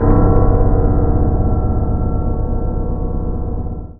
55y-pno01-g1.wav